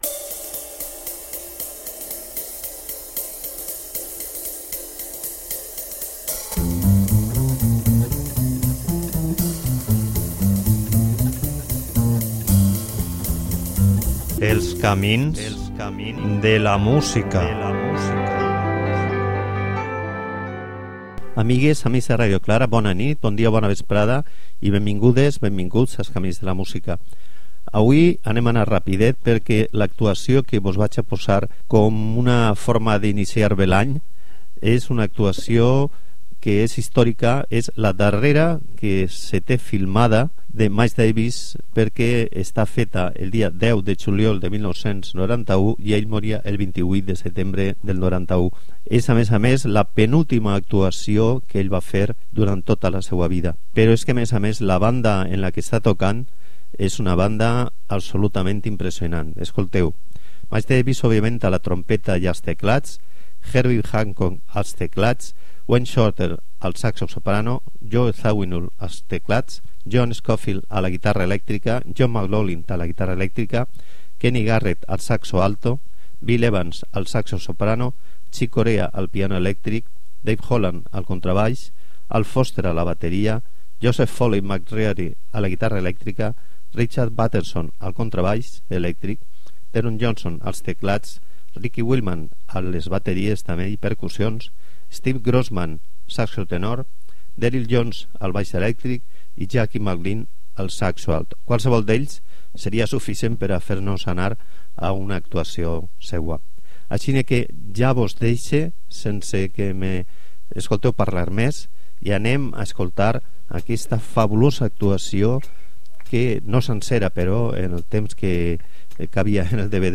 Pur directe!!